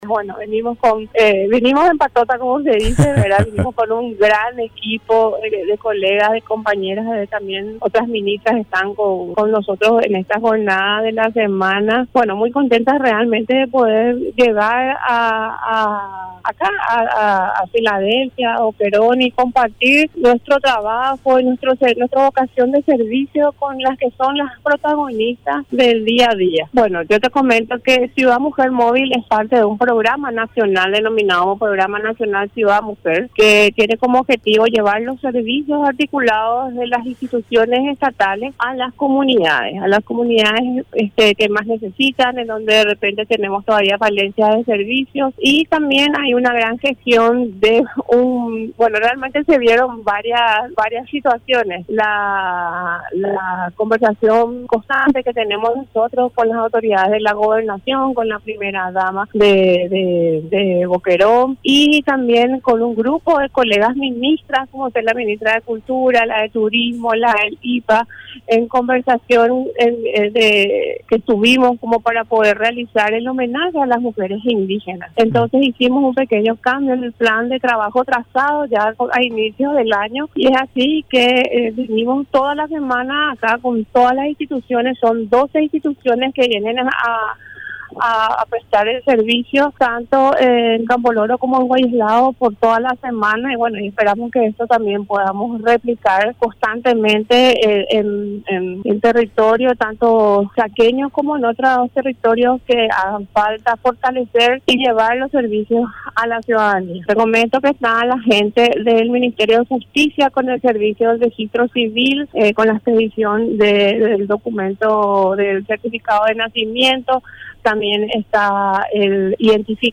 Entrevistas / Matinal 610
Entrevistado: Cynthia Figueredo
Estudio Central, Filadelfia, Dep. Boquerón